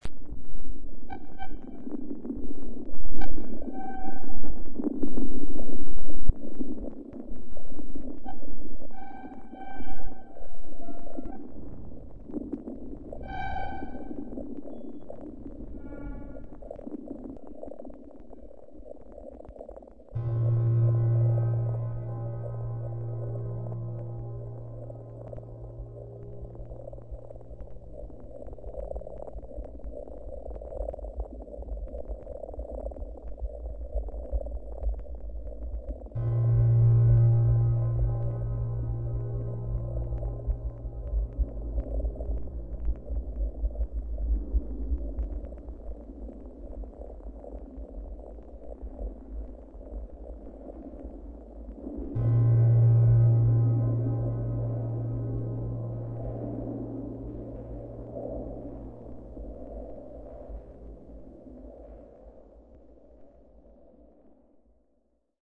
Cette installation sonore vient se superposer aux sonorités quotidiennes d’un lieu public, d’un lieu de vie sociale.
Les sons, diffusés à faible volume, sous un plafond de colonnes sonores, nécessitent pour être réellement perçus une démarche d’écoute volontaire de la part de l’auditeur.